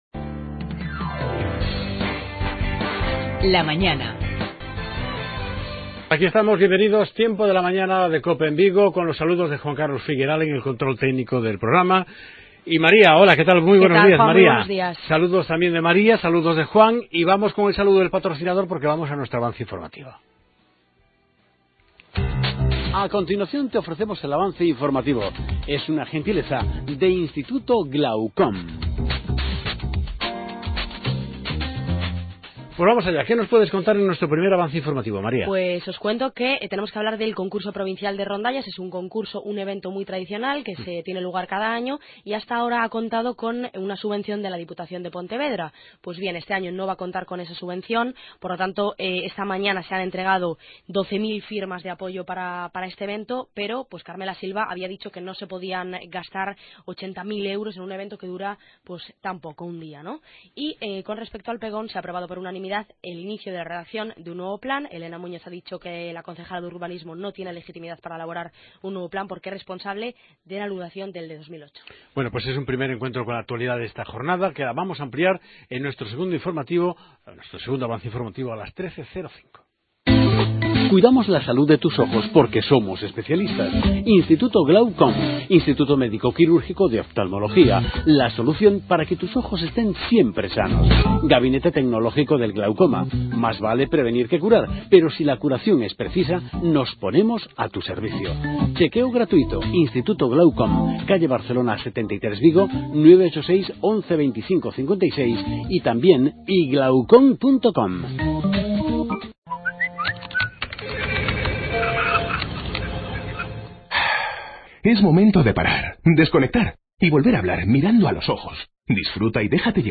Redacción digital Madrid - Publicado el 18 dic 2015, 14:43 - Actualizado 18 mar 2023, 04:39 1 min lectura Descargar Facebook Twitter Whatsapp Telegram Enviar por email Copiar enlace Entrevista a Ana Pastor, Cabeza de Cartel del PP en Pontevedra al Congreso de los Diputados y a Irene Garrido, Doctora en Economía y número dos de la candidatura del Partido Popular.